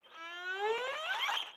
erhu model sinusoids sms-tools sound effect free sound royalty free Memes